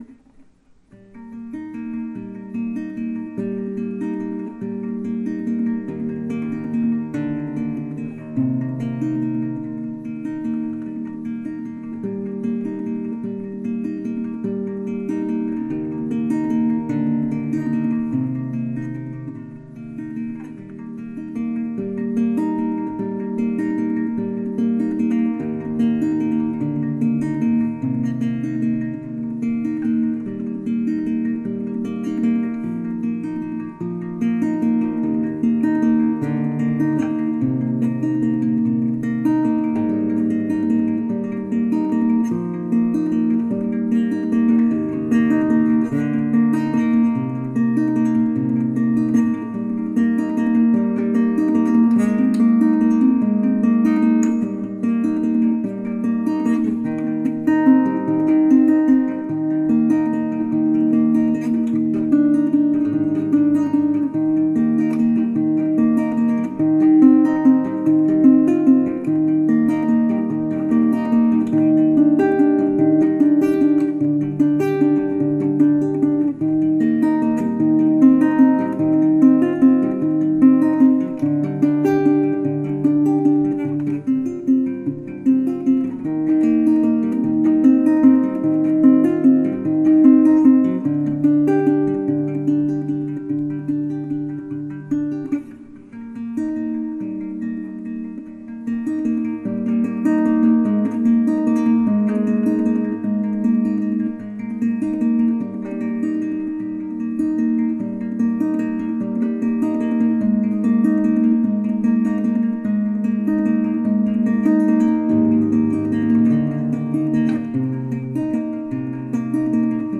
タグ: ギター、ロマンチック、バレンタイン、フォーク